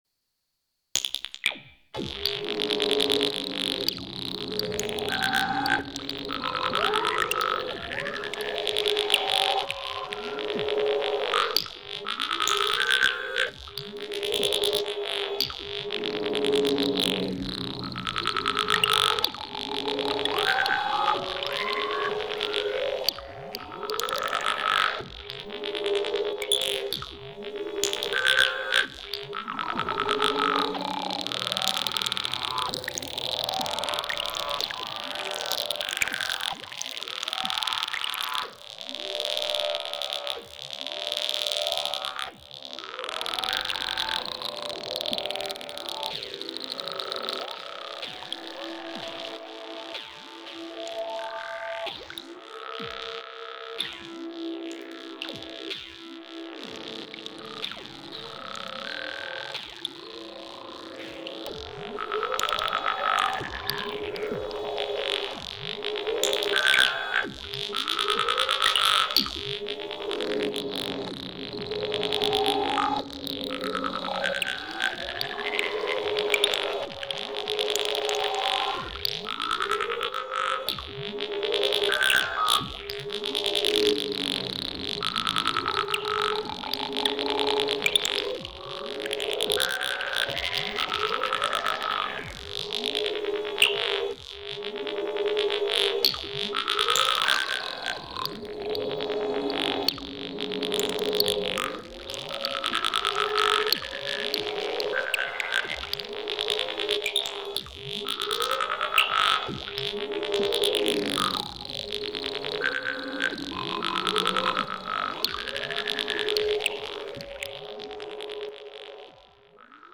3x Alloy + FX track. Mostly achieved by pitching things down very low, modulating and p-locking stuff, lots of resonance and changing the amp to adsr.